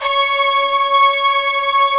Index of /90_sSampleCDs/Optical Media International - Sonic Images Library/SI1_Fast Strings/SI1_Fast Tutti